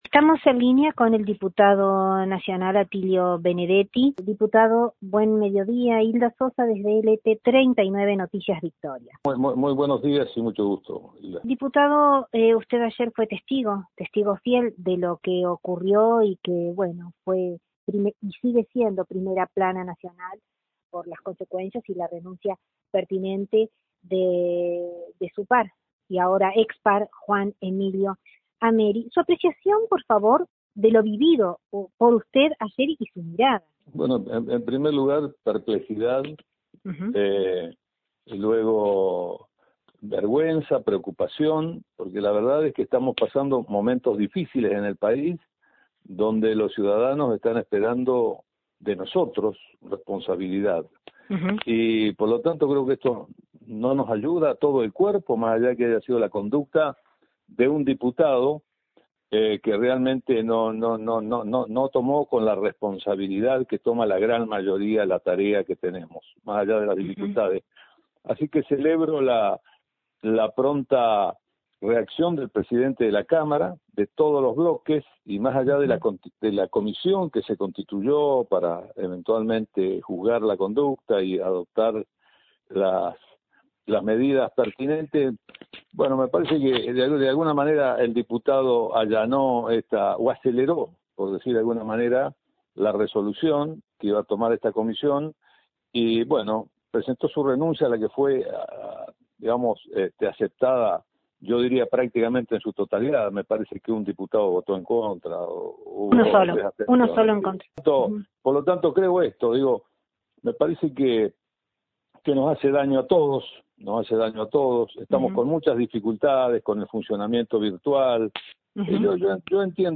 “Fue una falta contra el decoro de la Cámara de Diputados” dijo a LT39 NOTICIAS el legislador Atilio Benedetti testigo presencial de lo acaecido en el Congreso de la Nación
“Perplejidad, vergüenza y preocupación”, fueron las tres palabras dichas enfáticamente por el legislador radical, en un principio; para luego hacer hincapié en la poca responsabilidad con la que ha asumido su cargo el “renunciado” Juan Emilio Ameri.